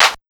AMB147CLAP-R.wav